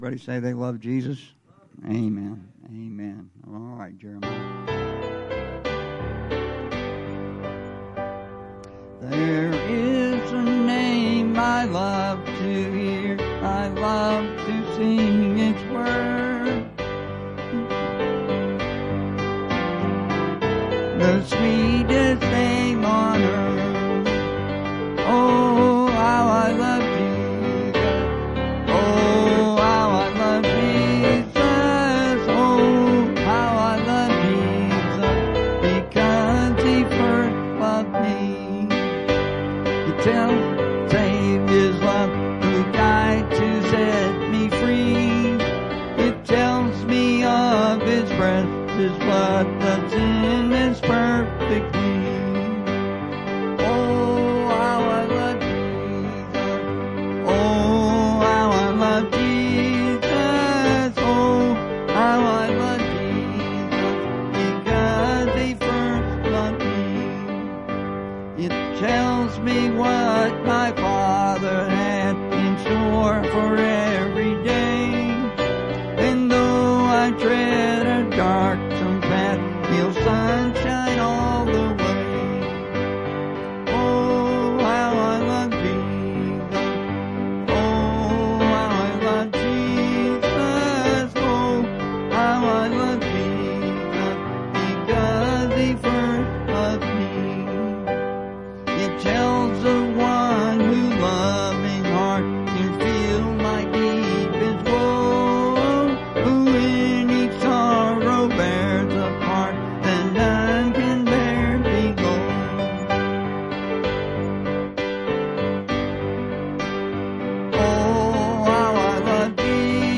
Bible Text: Colossians 1:24-29 | Preacher